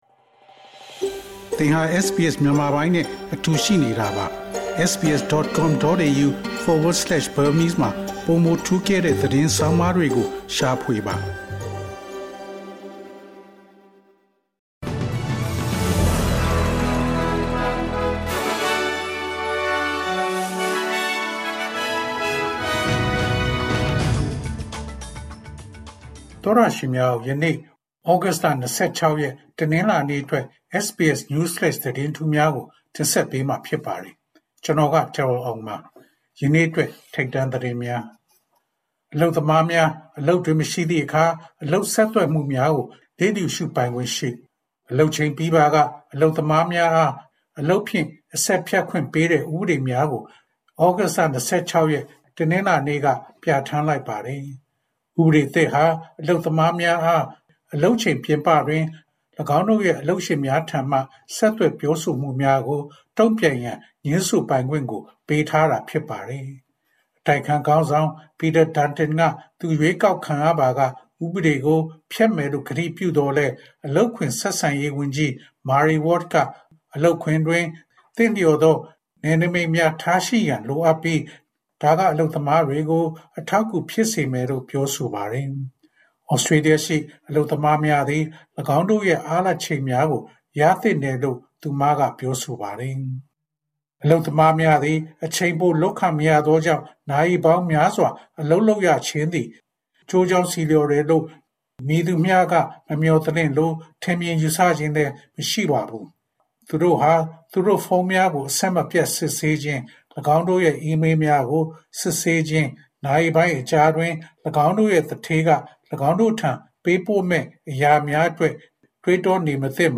ဩဂုတ်လ ၂၆ ရက် ဗုဒ္ဓဟူးနေ့ SBS Burmese News Flash သတင်းများ။